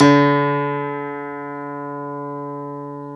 Index of /90_sSampleCDs/Club-50 - Foundations Roland/PNO_xTack Piano/PNO_xTack Pno 1D